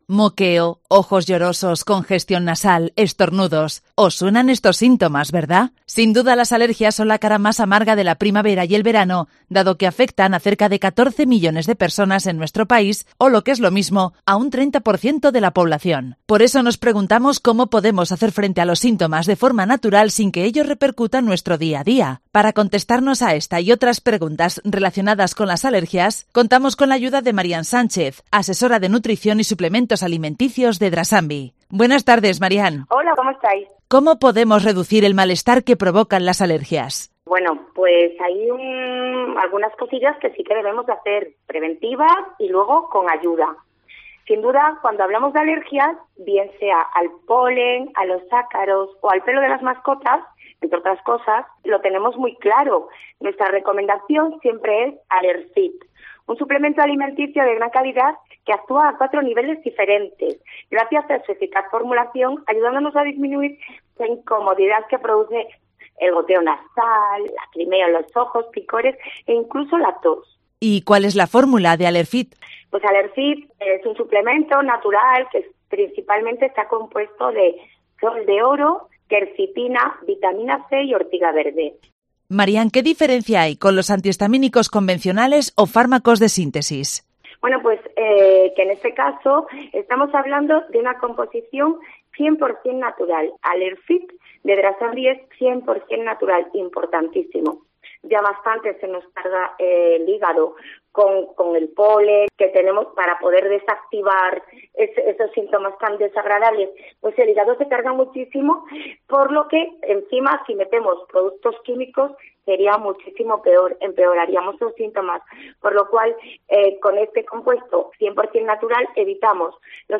participa hoy en el programa local de Cope León para ayudarnos a hacer frente a los síntomas de las alergias de forma natural sin que ello repercuta en nuestro día a día